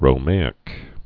(rō-māĭk)